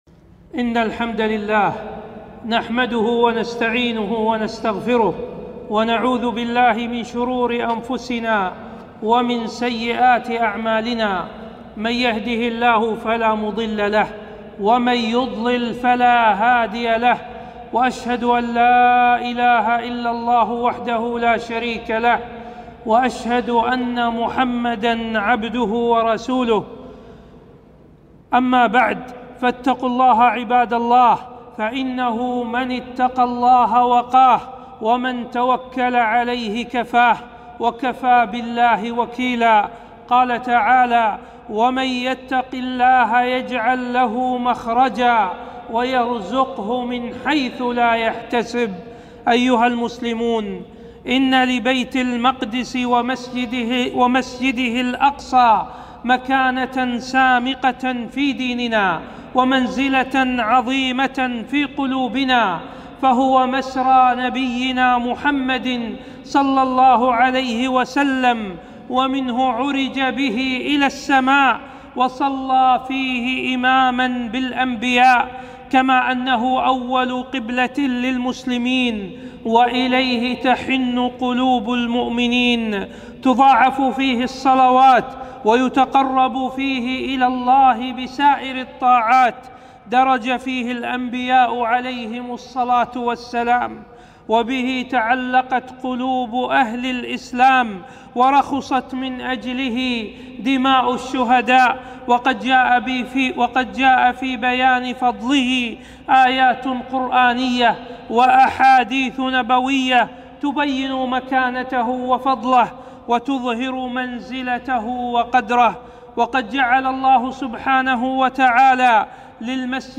خطبة - مكانة الأقصى في الإسلام